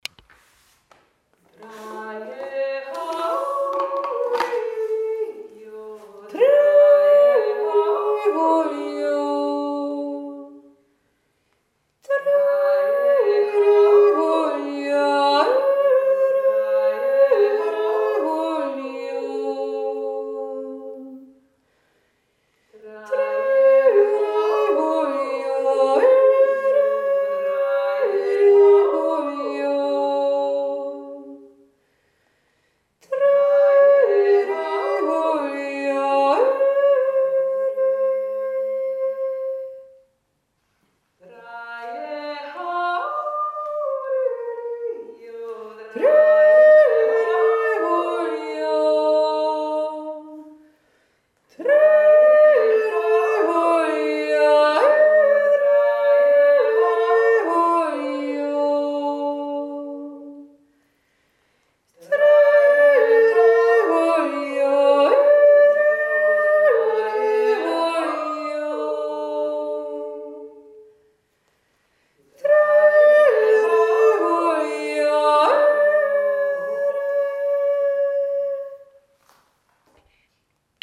1. und 2. Stimme